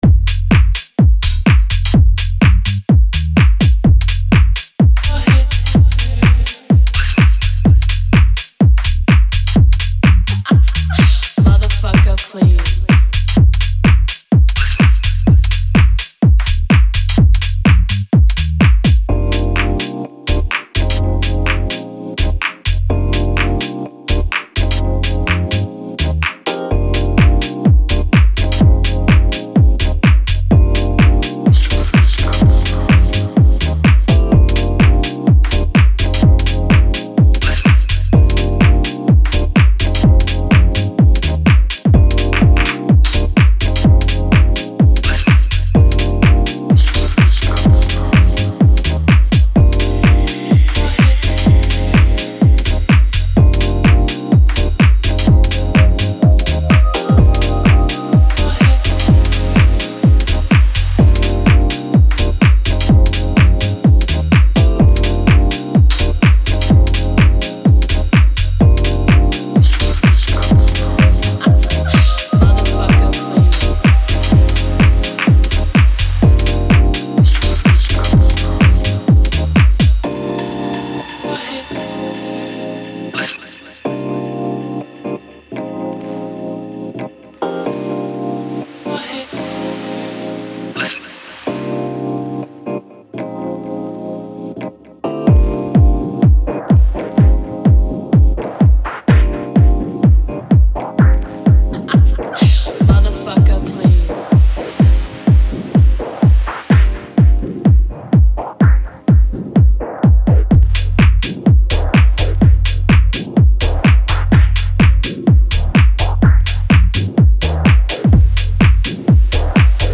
signature style groover
dreamy rhodes
sturdy drive that never lets you stop moving